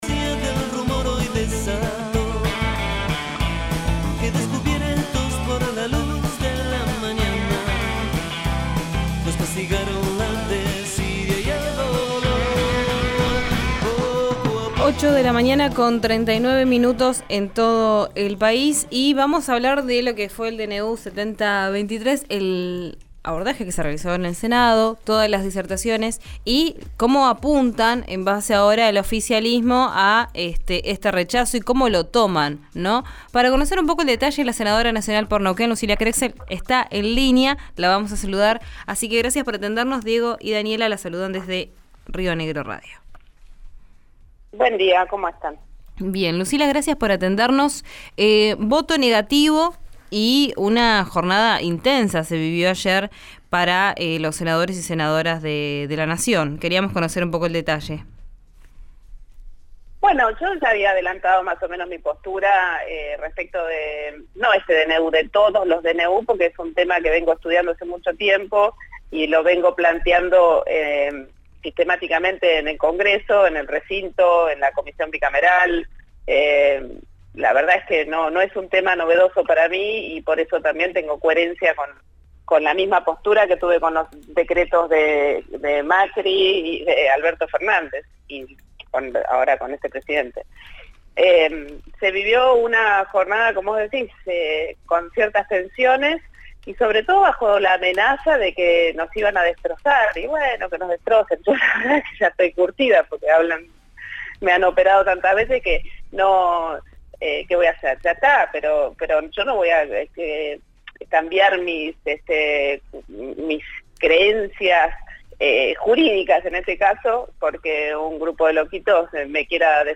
Escuchá a la senadora Lucila Crexell en RÍO NEGRO RADIO: